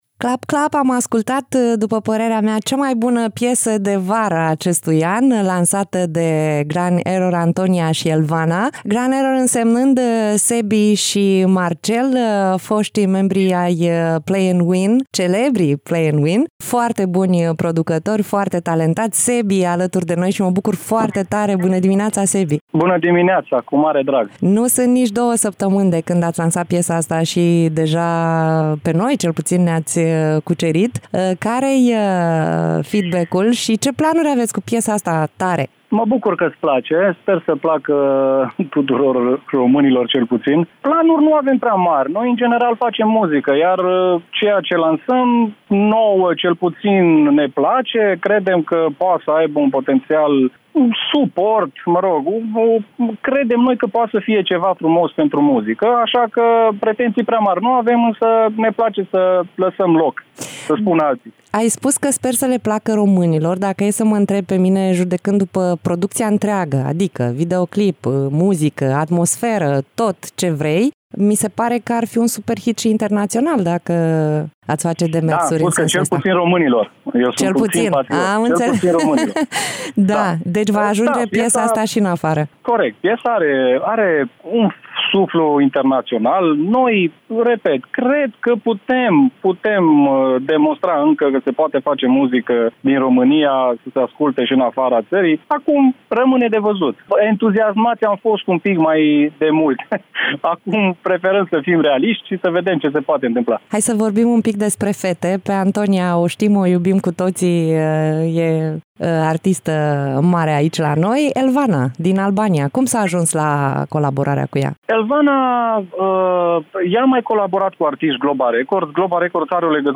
(INTERVIU)